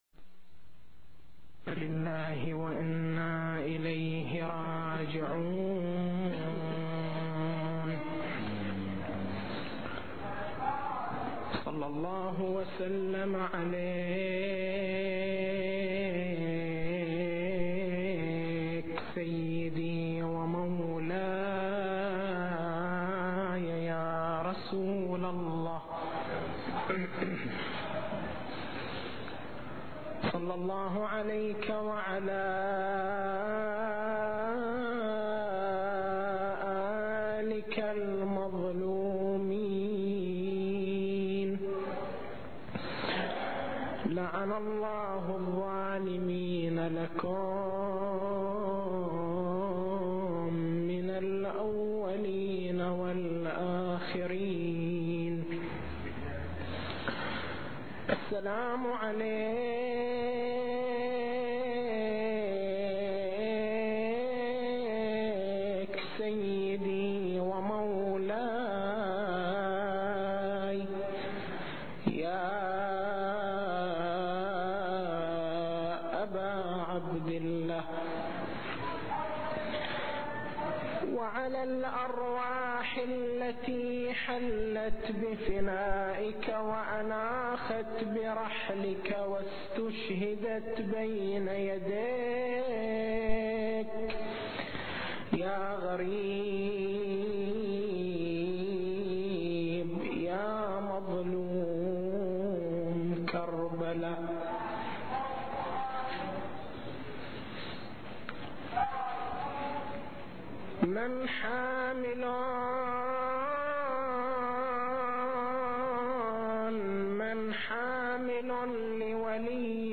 تاريخ المحاضرة: 01/01/1424 نقاط البحث: المداليل المستفادة من الكلمة الزينبية حكم صيام يوم عاشوراء التسجيل الصوتي: تحميل التسجيل الصوتي: شبكة الضياء > مكتبة المحاضرات > محرم الحرام > محرم الحرام 1424